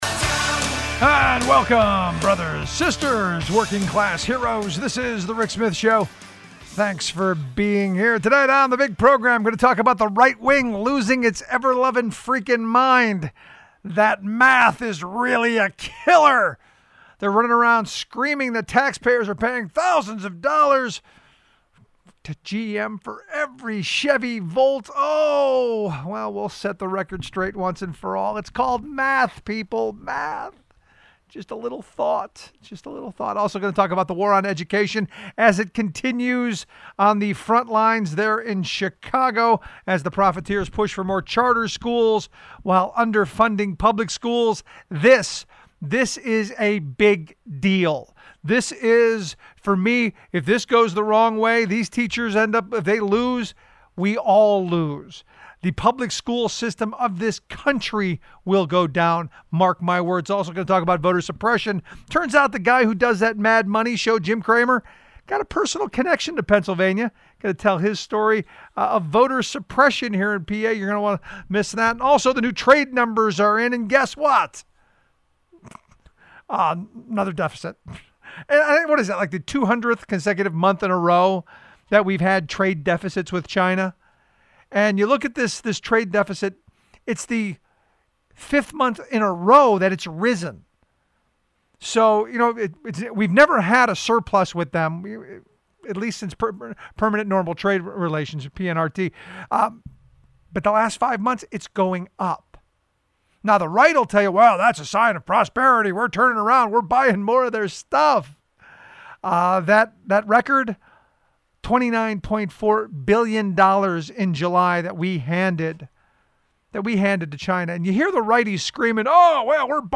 My interview starts at 1:36:40.